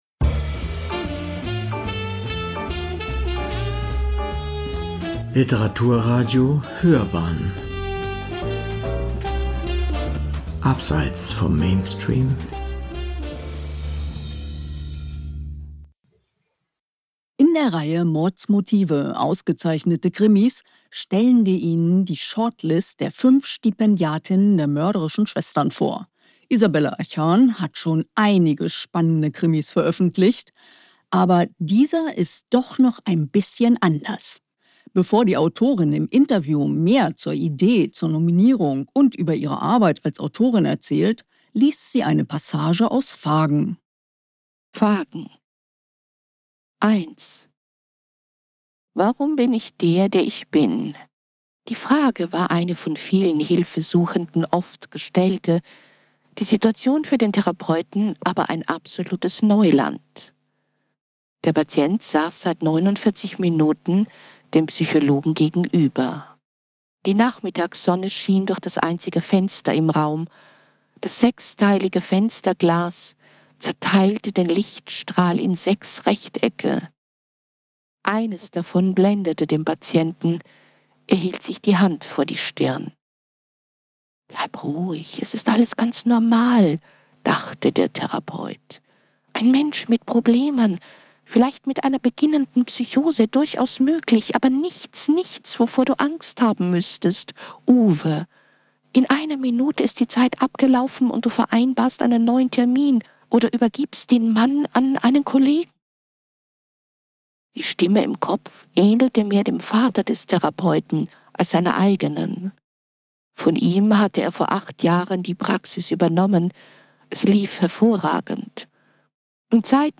(Hördauer ca. 33 Minuten, Gesprächsbeginn bei 07:35 Minuten)